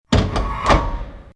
CHQ_FACT_switch_popup.mp3